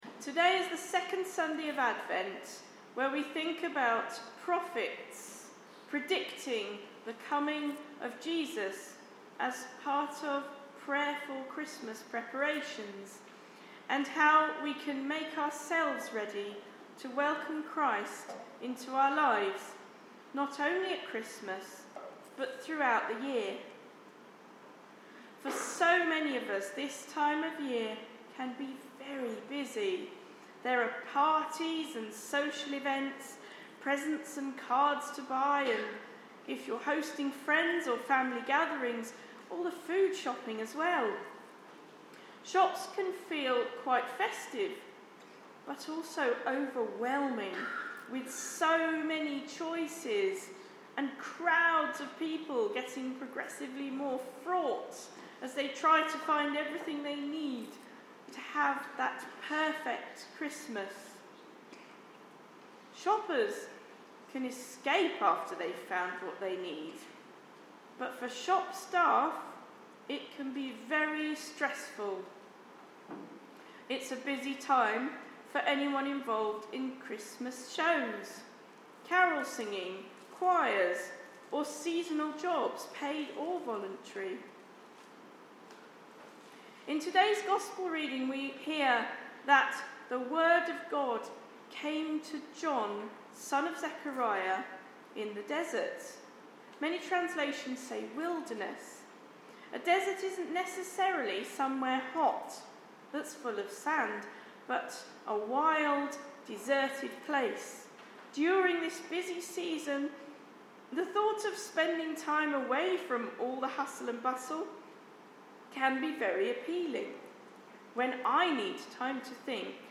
Sermon preached on the second Sunday in Advent, 8 December 2024